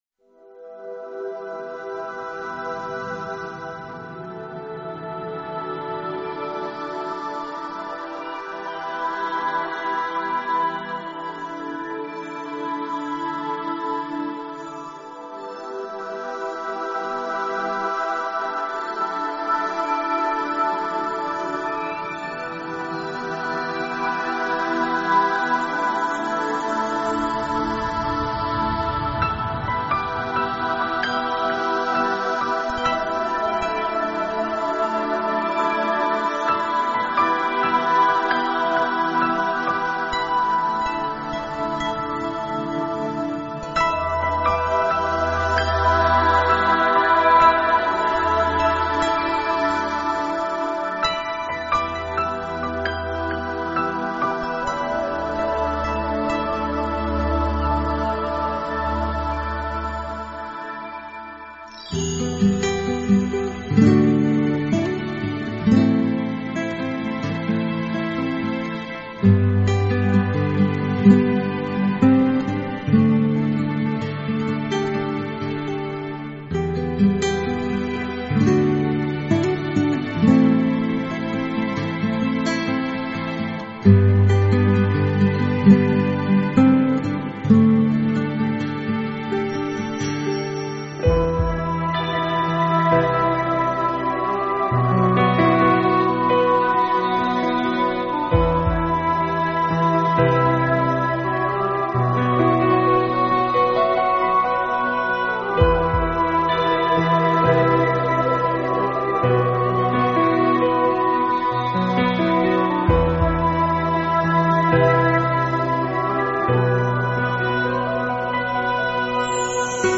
类型: New Age
这种音乐是私密的，轻柔的，充满庄严感并总是令人心胸开阔。